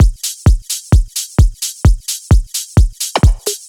Flanged Hats 02.wav